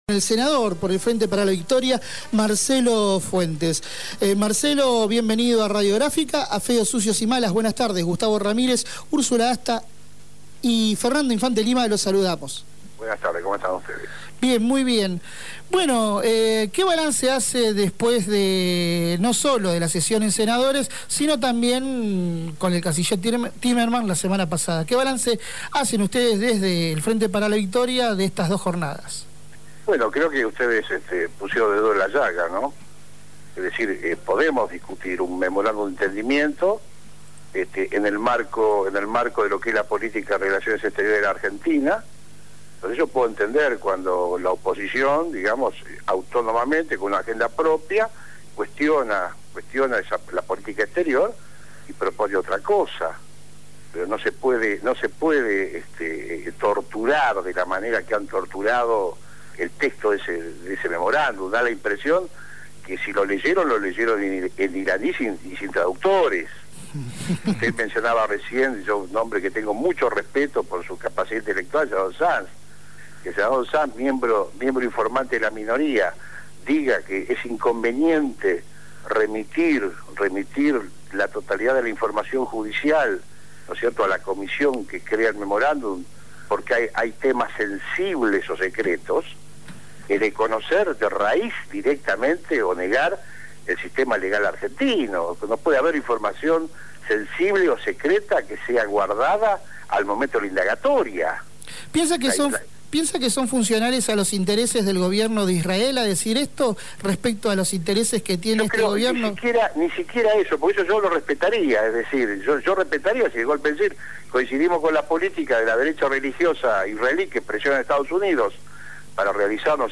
El Senador Nacional Marcelo Fuentes criticó a la oposición y la acusó de alinearse con los poderes mediáticos. En diálogo con Feos, Sucios y Malas, el congresista se refirió al memorándum de entendimiento con Irán para  que la Justicia argentina tome declaración indagatoria en ese país a presuntos responsables del atentado de la AMIA.